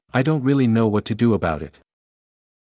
また、アクセント（強弱）とともに、文全体のイントネーションも、アメリカ英語は文章が全体が平坦で単調なイントネーションを持つ傾向があります。
アメリカ英語
AE-intonation.mp3